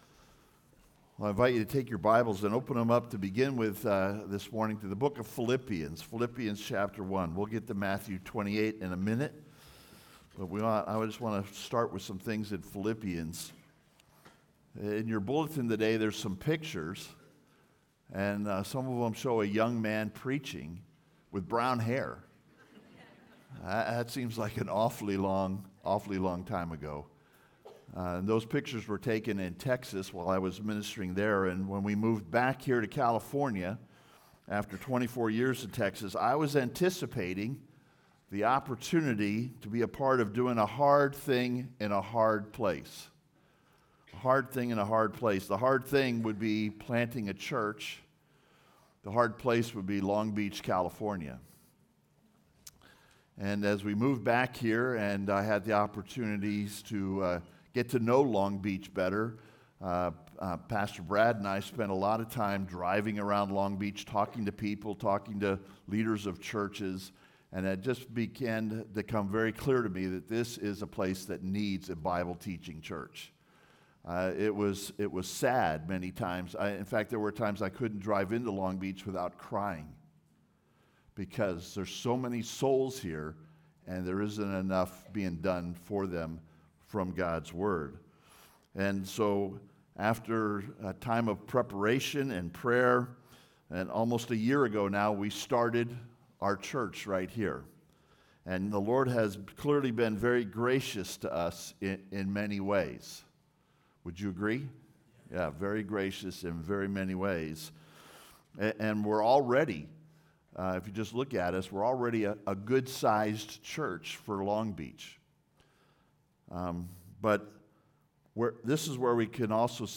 Doing the King's Work (Sermon) - Compass Bible Church Long Beach